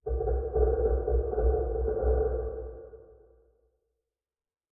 heavy_click2.ogg